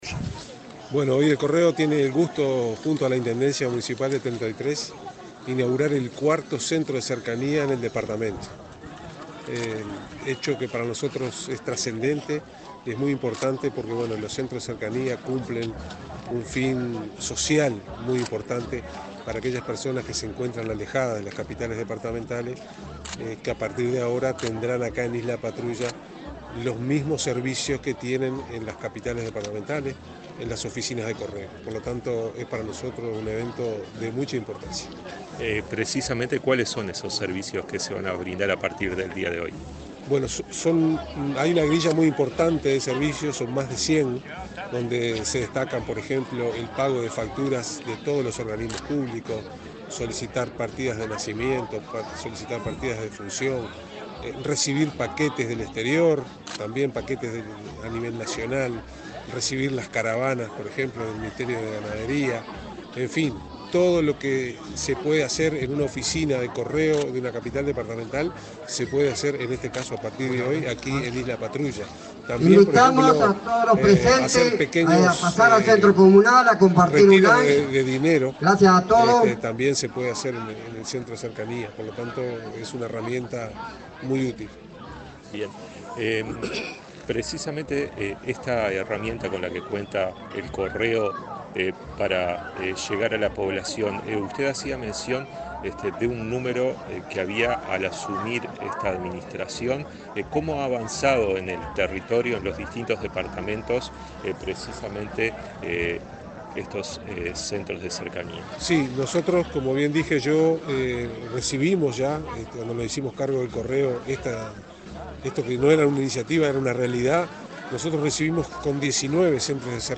Entrevista al presidente del Correo Uruguayo, Rafael Navarrine
El presidente de la Administración Nacional de Correos, Rafael Navarrine, dialogó con Comunicación Presidencial en el departamento de Treinta y Tres,